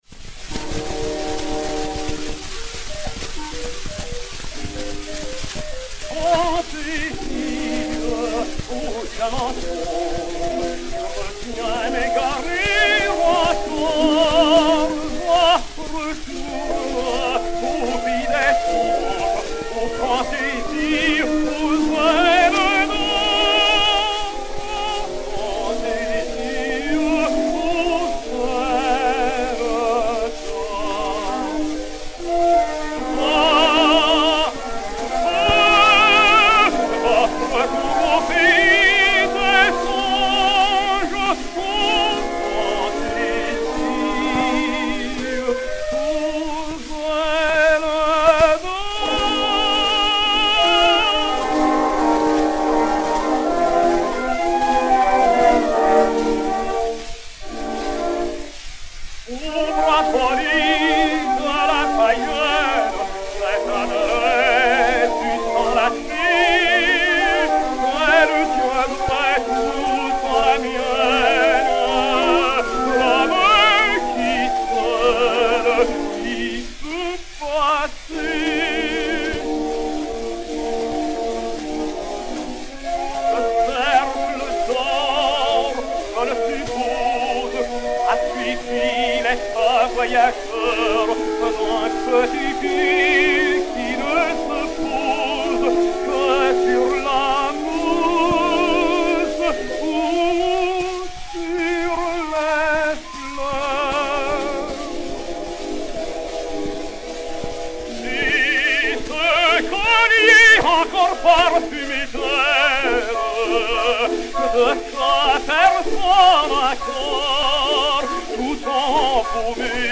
Léon Campagnola (Mylio) et Orchestre
Disque Pour Gramophone 4-32357, mat. 18606u, enr. à Paris le 03 janvier 1914